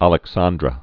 lĕk-sändrə), Vicente 1898-1984.